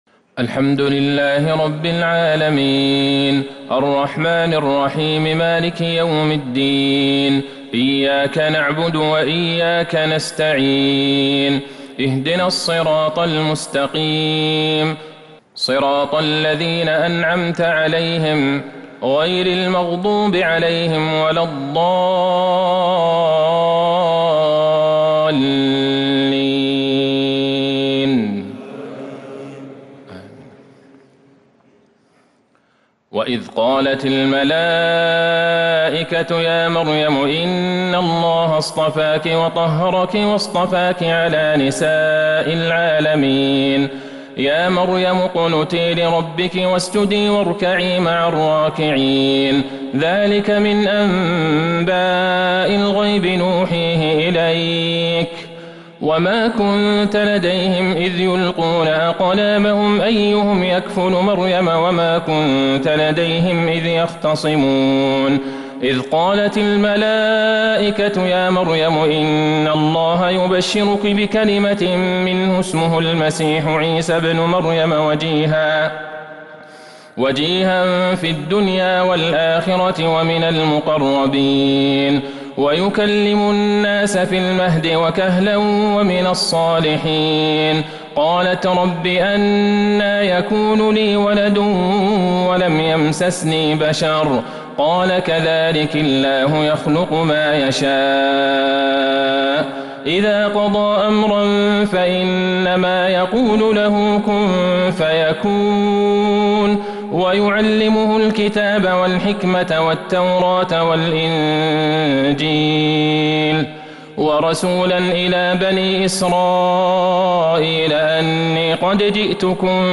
صلاة التراويح | ليلة ٤ رمضان ١٤٤٢هـ | سورة آل عمران 42-92 | Taraweeh 1st night Ramadan 1442H > تراويح الحرم النبوي عام 1442 🕌 > التراويح - تلاوات الحرمين